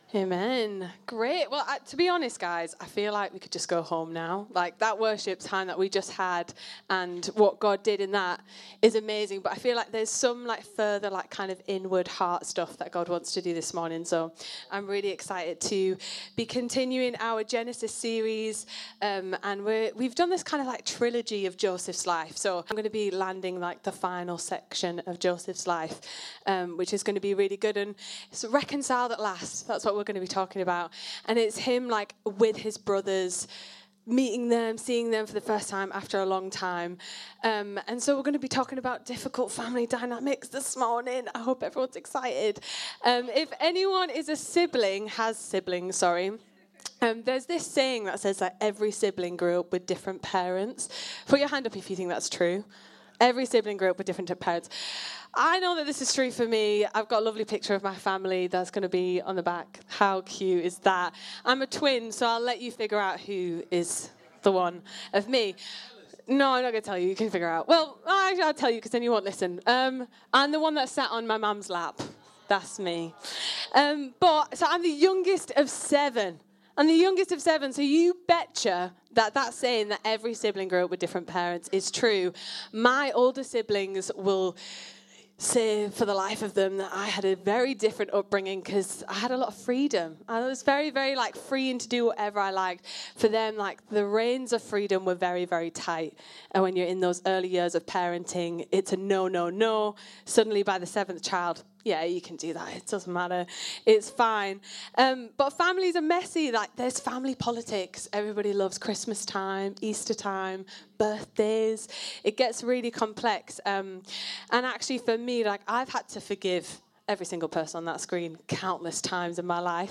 Download Joseph: Reconciled At Last | Sermons at Trinity Church